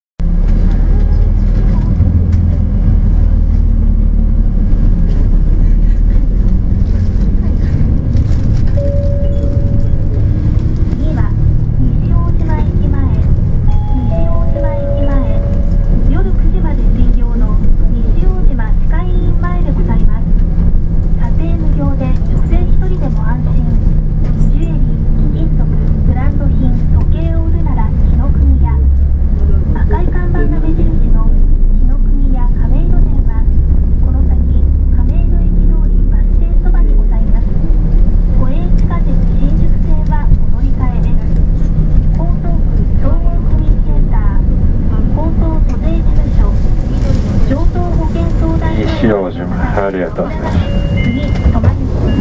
音声合成装置   レゾナント・システムズ(旧ネプチューン)